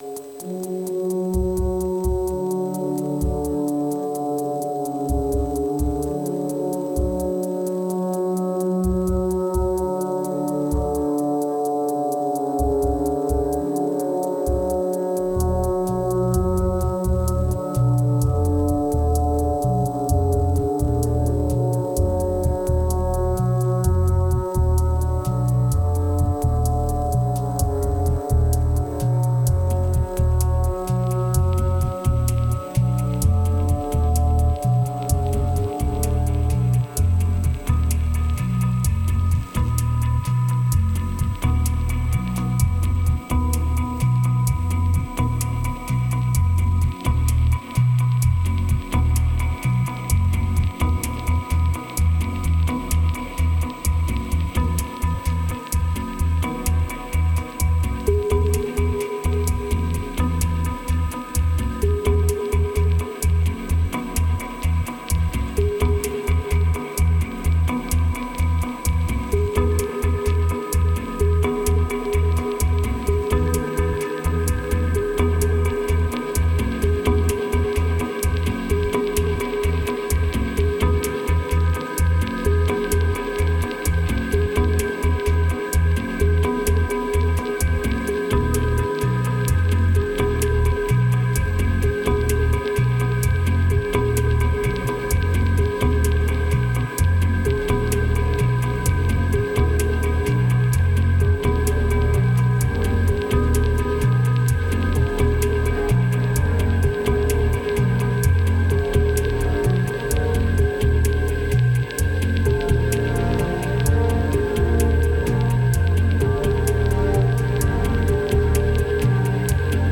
Crée à 88bpm puis ralenti à 32